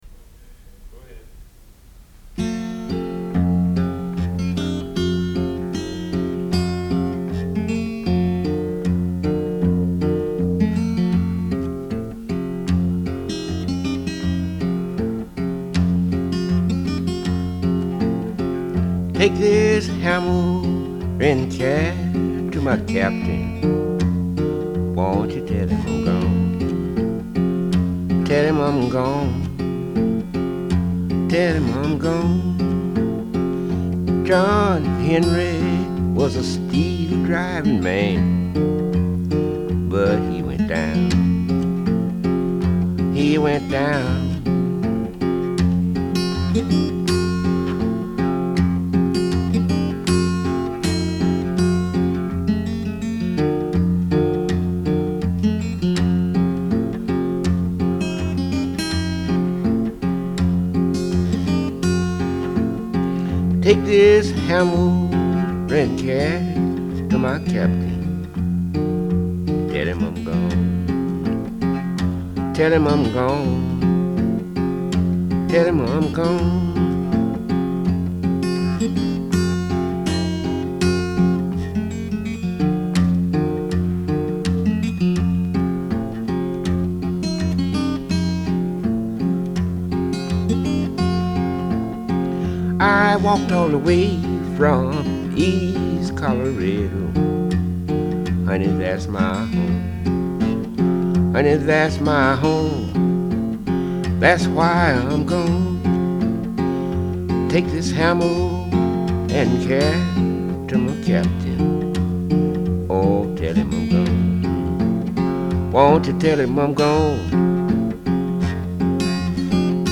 Genre : Rock, Blues, Folk